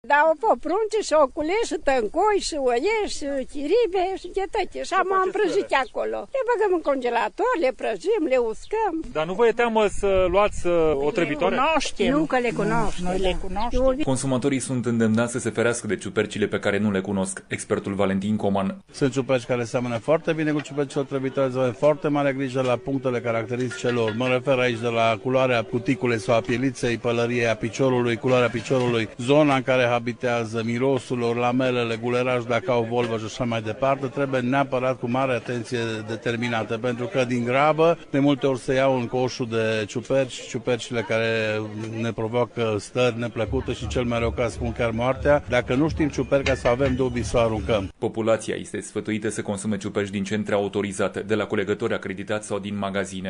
Cu detalii corespondentul RRA